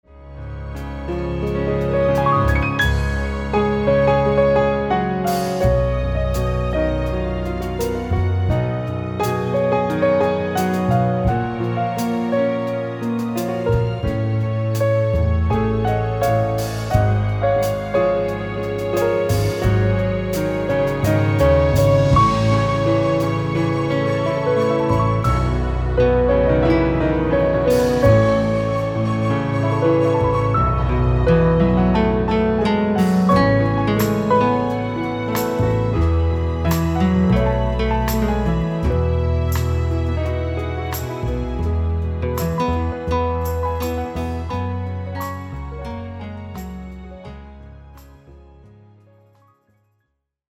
Pianist
instrumental recordings